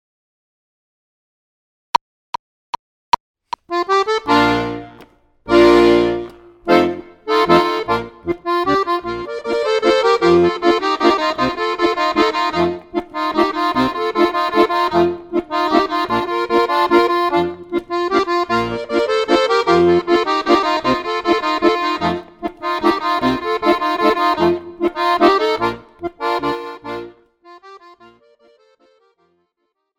Besetzung: Tuba